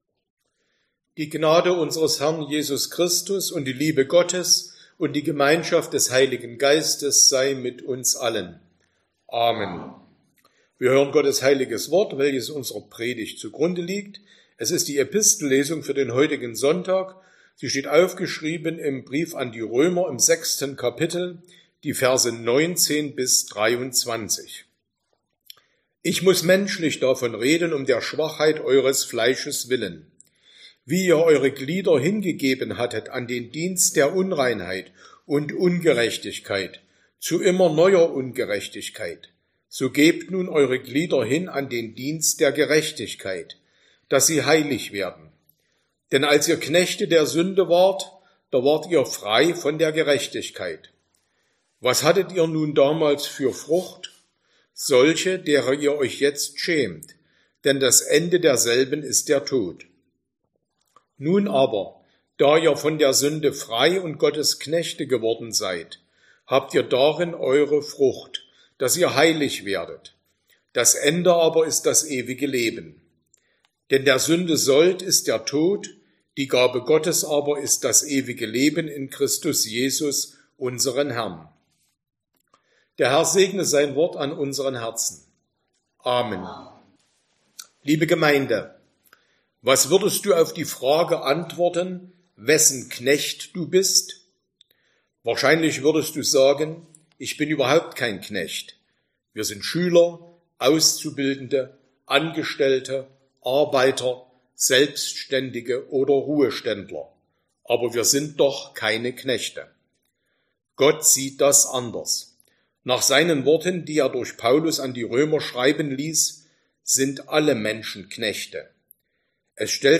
Sonntag nach Trinitatis Passage: Römer 6, 19-23 Verkündigungsart: Predigt « 6.